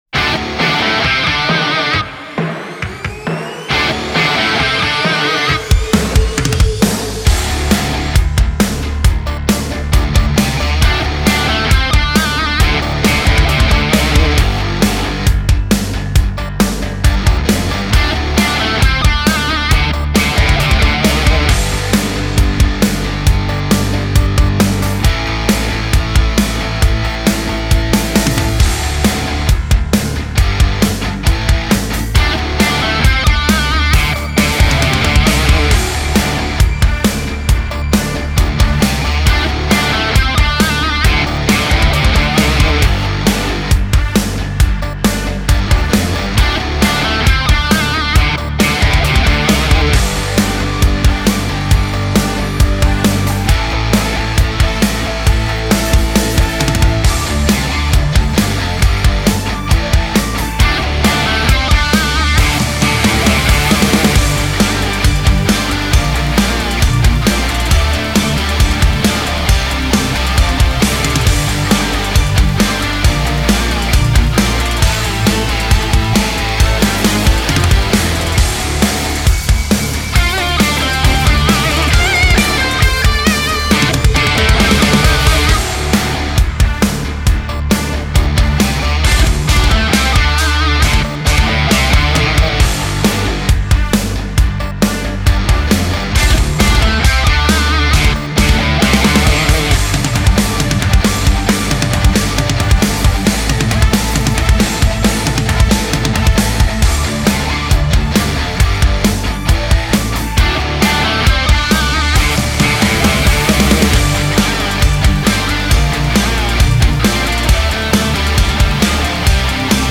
Дерзкая песня в рок-стиле для мальчиков и подростков.
Характер песни: серьёзный.
Темп песни: средний.
• Минусовка